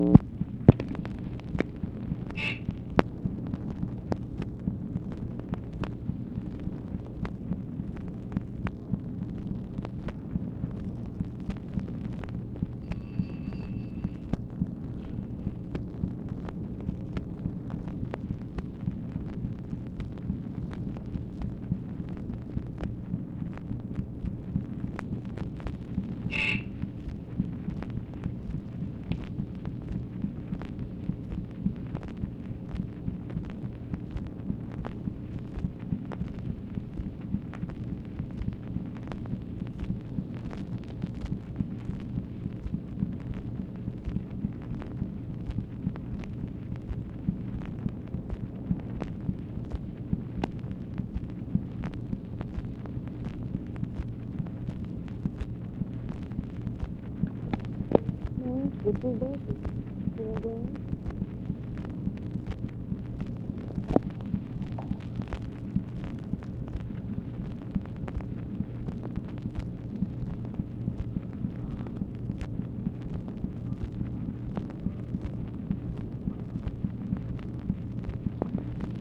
OFFICE NOISE, August 26, 1965
Secret White House Tapes | Lyndon B. Johnson Presidency